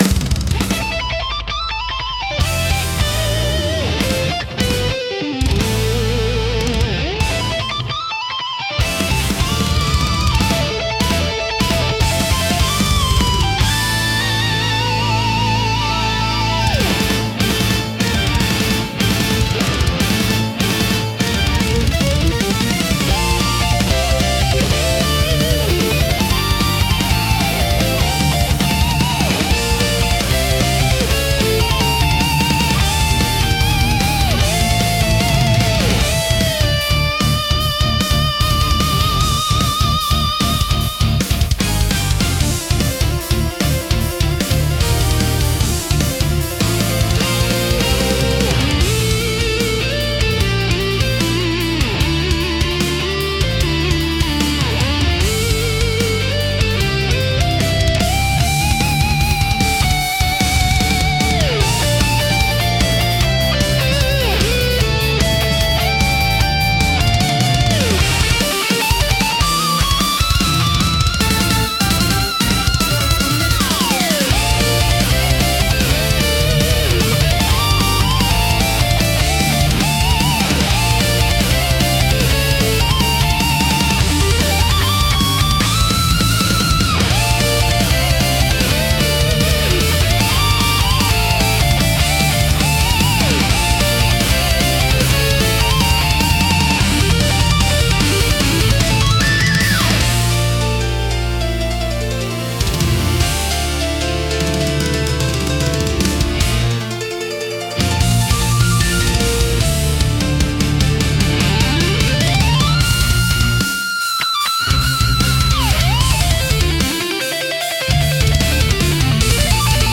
熱狂的で迫力あるサウンドが勝負や決戦の場面を盛り上げ、プレイヤーや視聴者の集中力と興奮を引き出します。